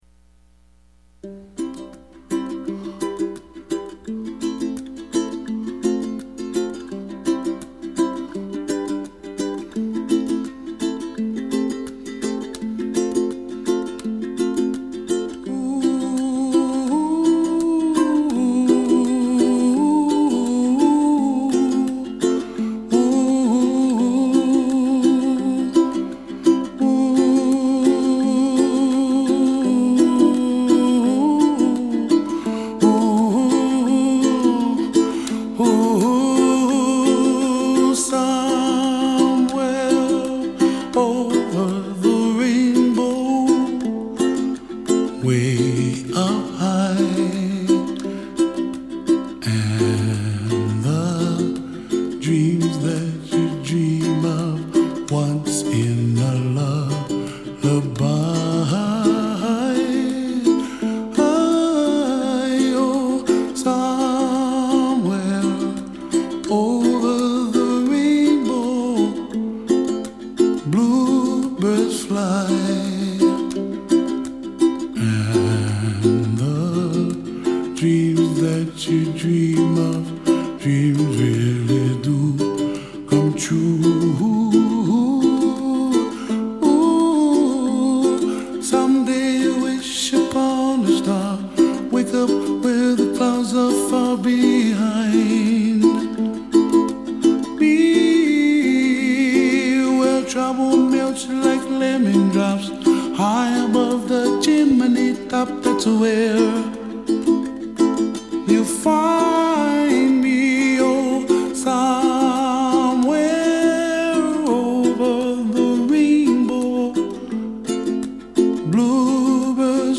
Tags: Sentimental Classic All time favorite Sweet Passionate